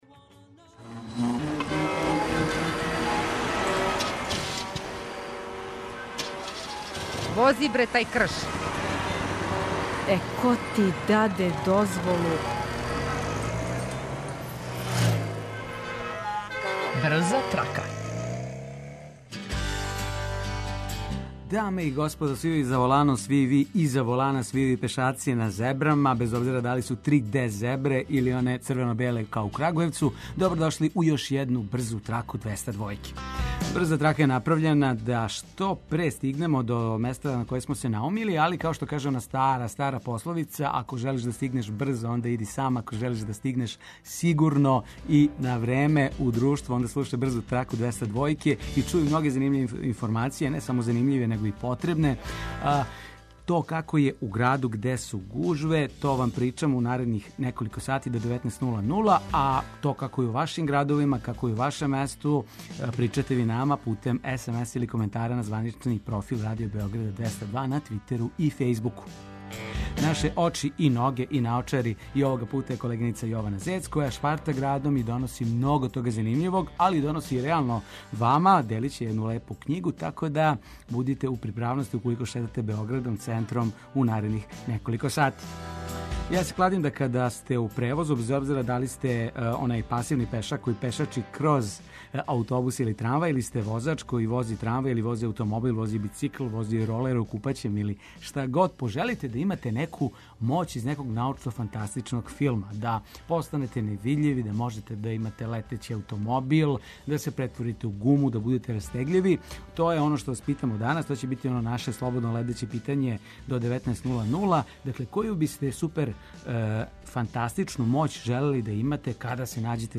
Слушаоци репортери јављају новости из свог краја, па нам се јавите и ви.